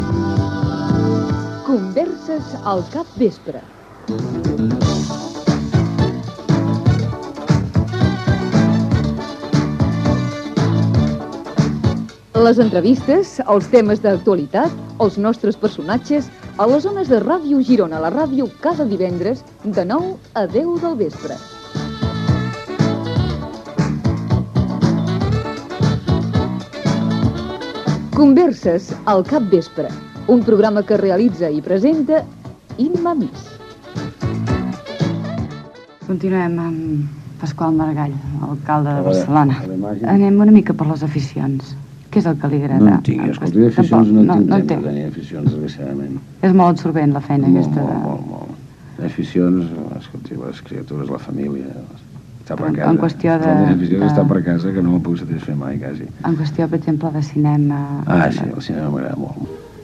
Entrevistes a personatges que passaven per la ciutat de Girona. En aquesta ocasió, Pasqual Maragall.